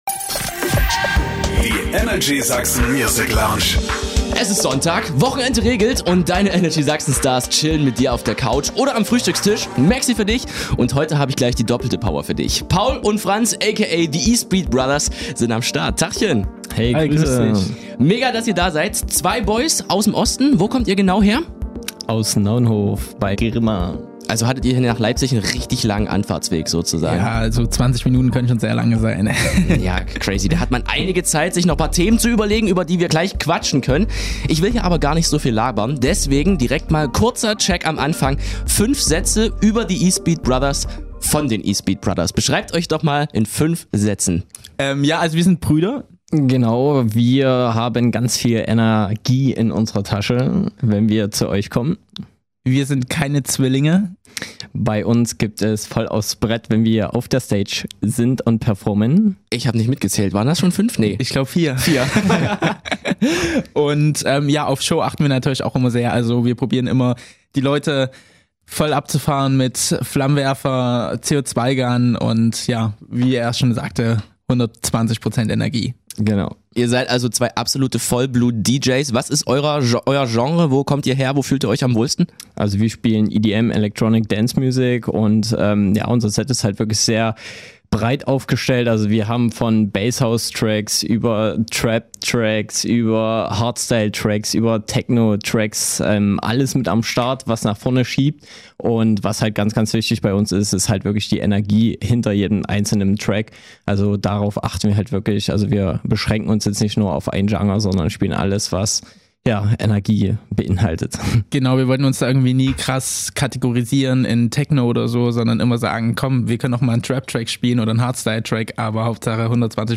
Beschreibung vor 2 Jahren Zwei Brüder aus Sachsen, die als DJ´s die Bühnen in Deutschland und der ganzen Welt stürmen.